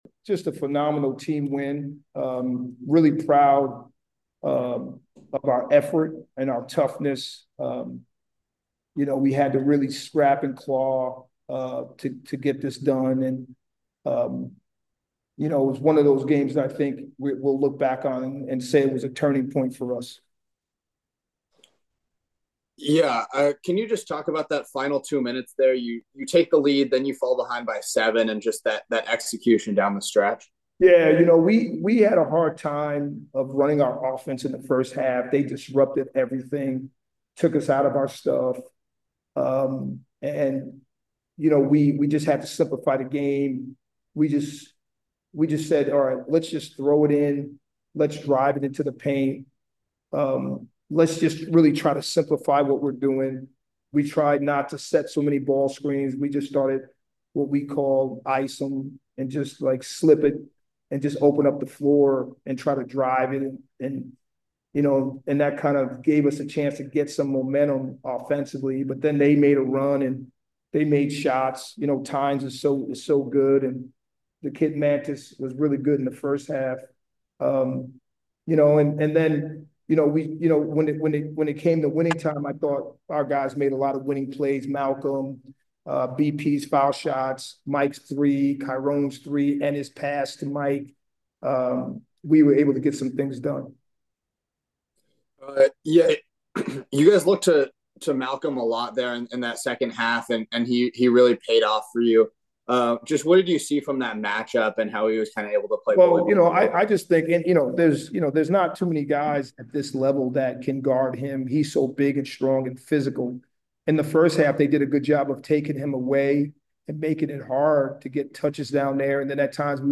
BU-Maine-Postgame.mp3